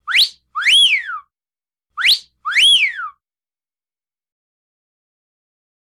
Звуки свиста, свистков
Свист мужчины при виде красивой девушки (волчий посвист)